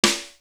Royal Oak Snare.wav